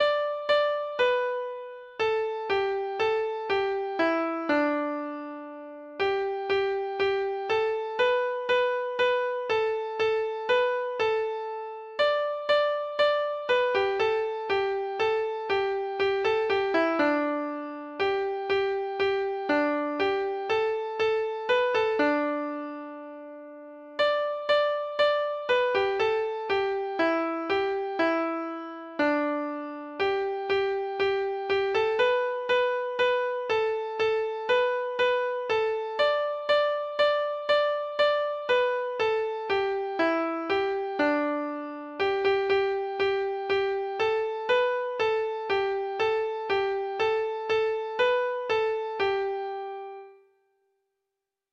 Folk Songs from 'Digital Tradition' Letter G Goodbye Old Friend
Treble Clef Instrument  (View more Intermediate Treble Clef Instrument Music)
Traditional (View more Traditional Treble Clef Instrument Music)